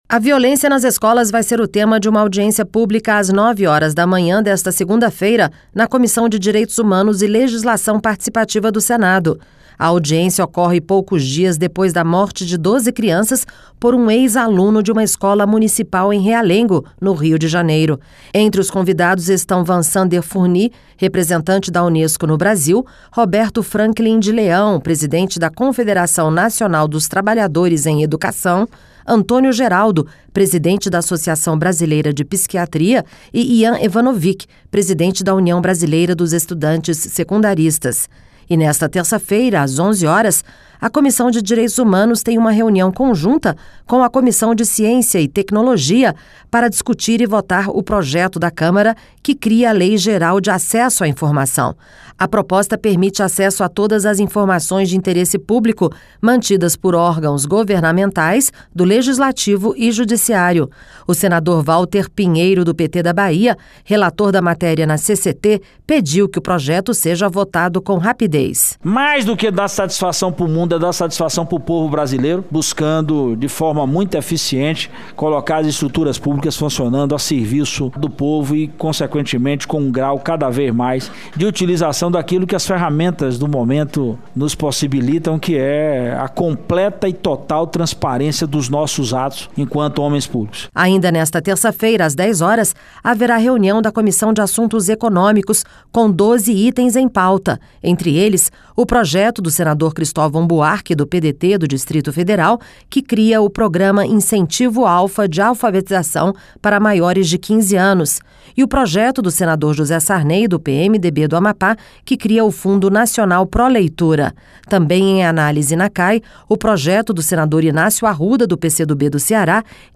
O senador Walter Pinheiro, do PT da Bahia, relator da materia na CCT pediu que o projeto seja votado com rapidez.